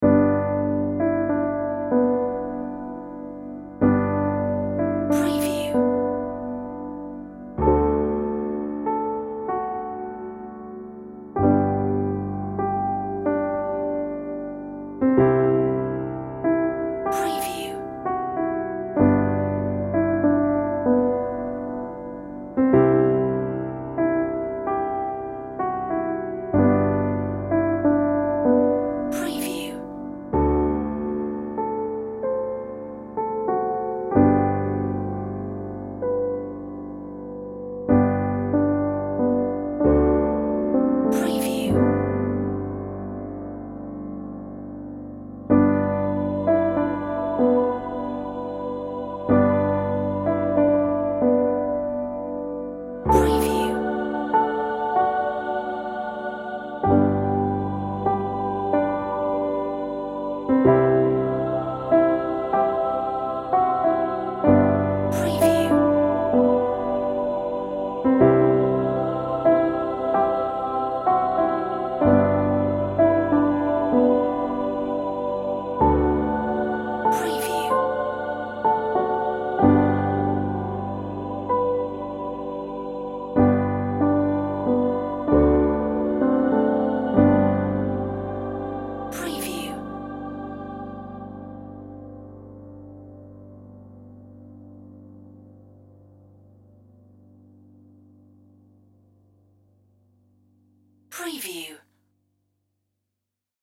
Piano version